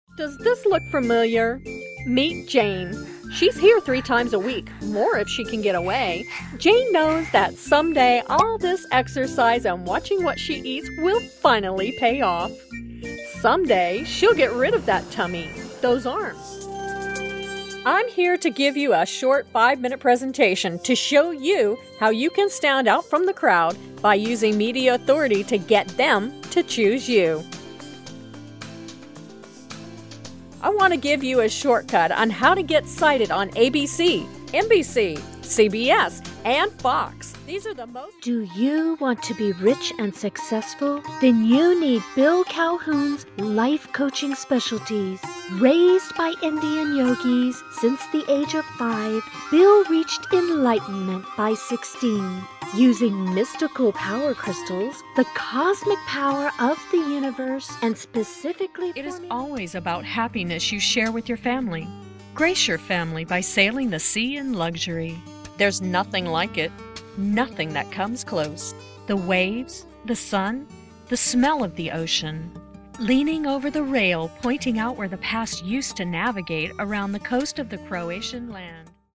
Voice Intonations: Bright, Energetic, Professional, Calming, Adult, Millennial, Child, Senior
englisch (us)
Sprechprobe: Sonstiges (Muttersprache):